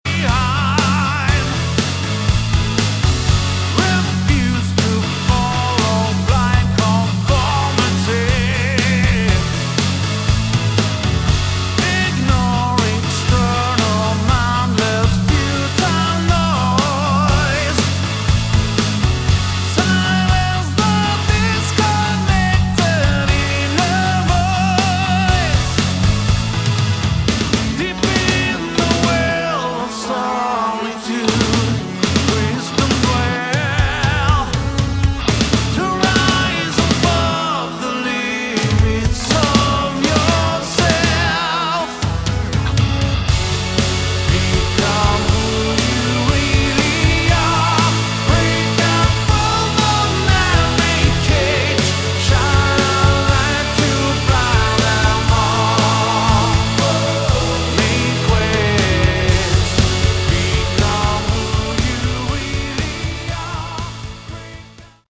Category: Melodic Rock
keyboards, vocals
drums
guitars
bass
vocals, guitars